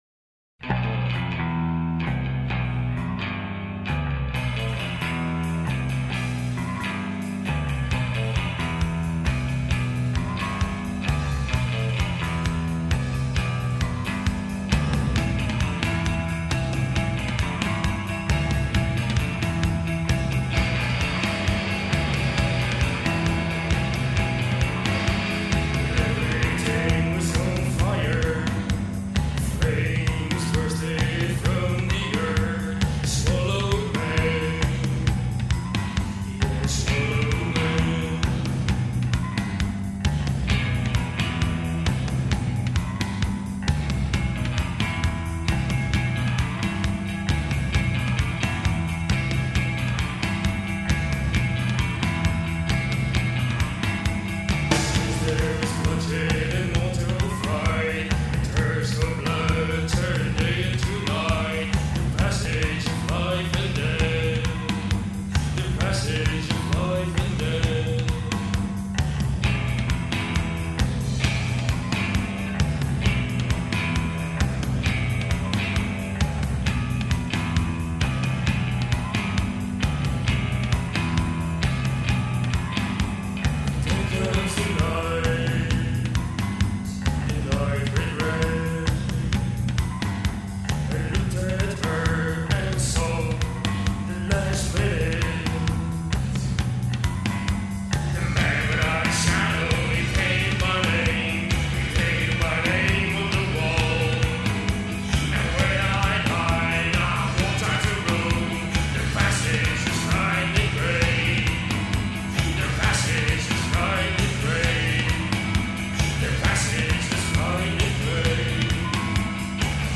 their sound was so close to Joy Division